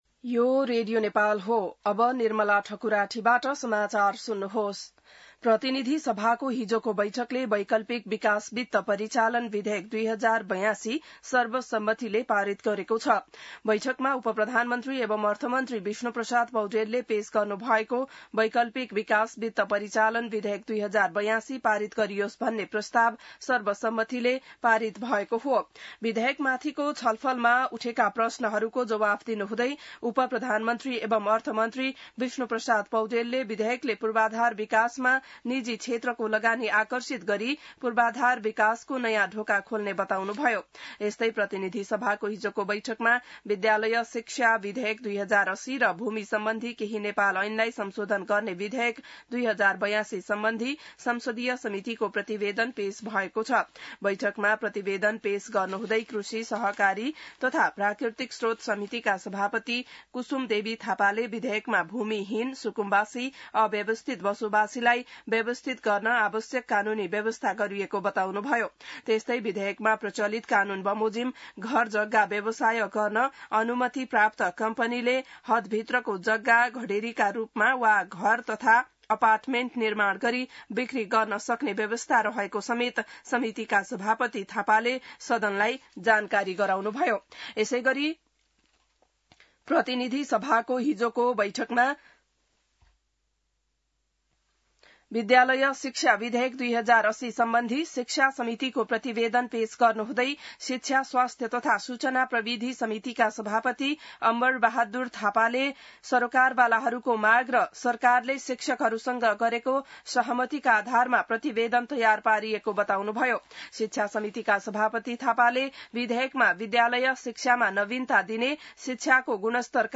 बिहान ६ बजेको नेपाली समाचार : ७ भदौ , २०८२